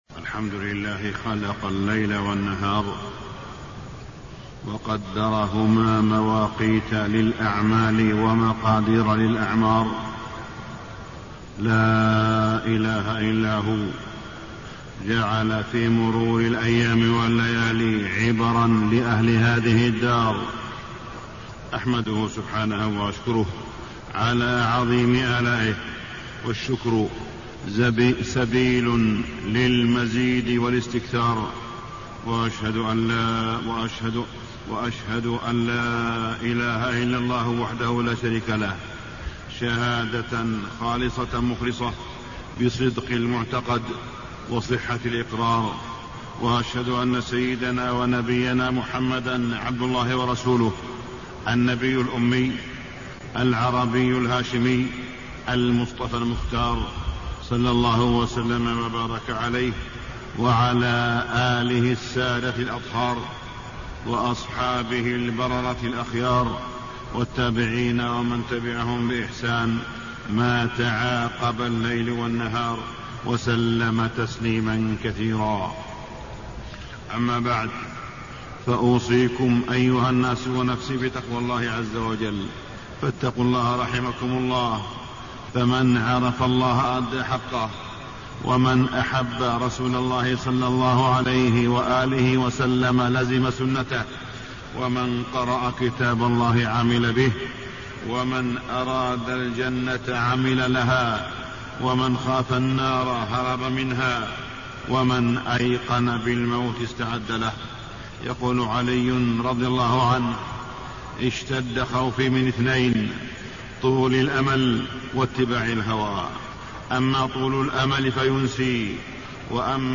تاريخ النشر ٢٩ ذو الحجة ١٤٣٢ هـ المكان: المسجد الحرام الشيخ: معالي الشيخ أ.د. صالح بن عبدالله بن حميد معالي الشيخ أ.د. صالح بن عبدالله بن حميد قصر الأمل وحسن العمل